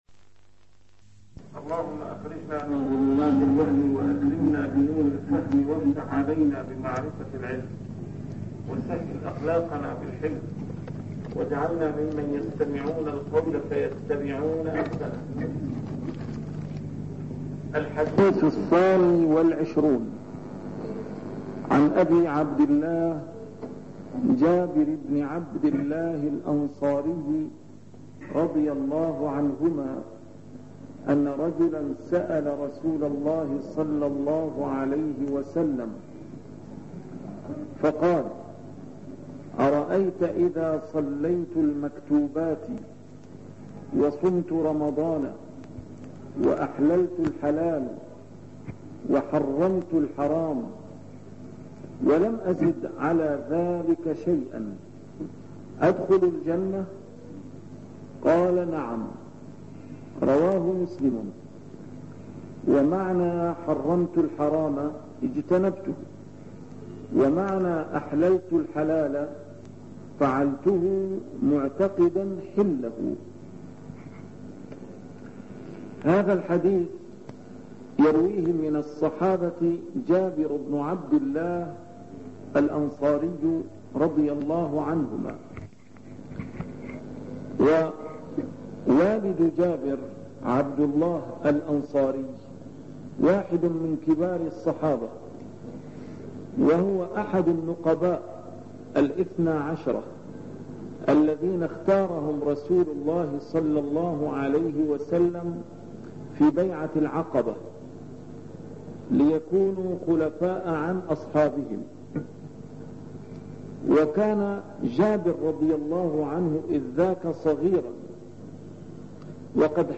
A MARTYR SCHOLAR: IMAM MUHAMMAD SAEED RAMADAN AL-BOUTI - الدروس العلمية - شرح الأحاديث الأربعين النووية - شرح الحديث الثاني والعشرون: حديث جابر ابن عبد الله (أرأيتَ إذا صليتُ المكتوبات) 69